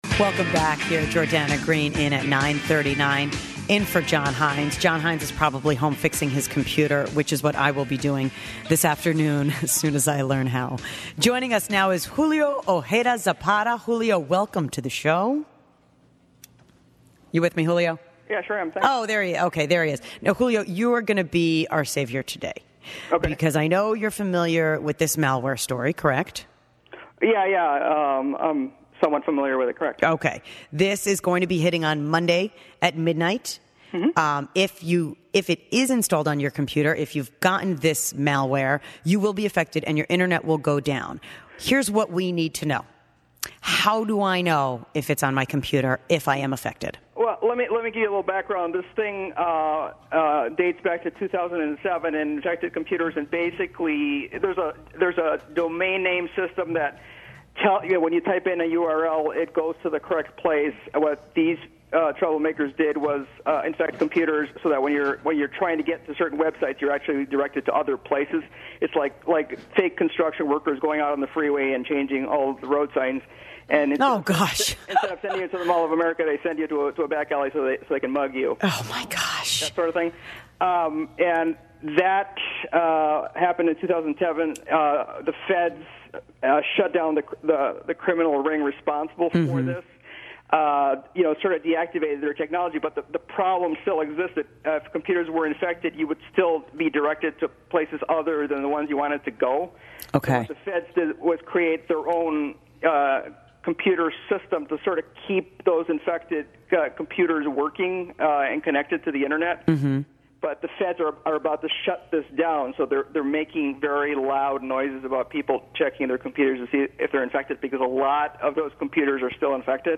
Talking malware on WCCO radio